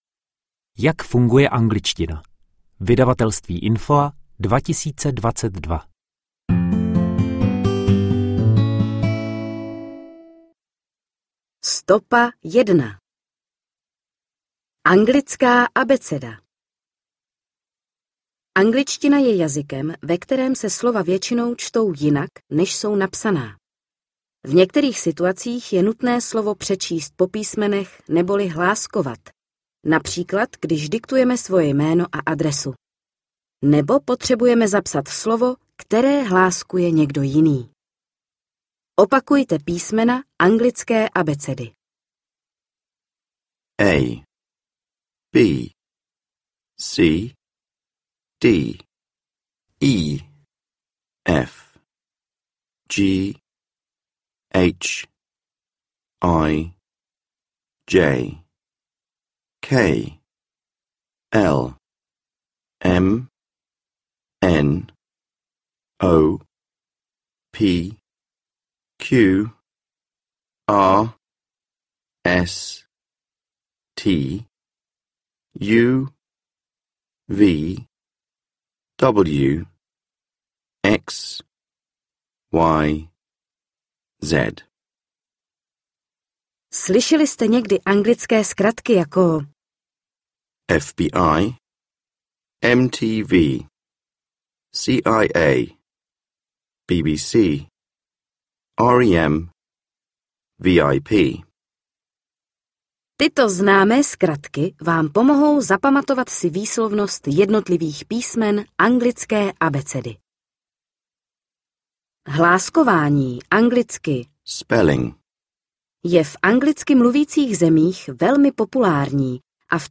Audio knihaJak funguje angličtina
Ukázka z knihy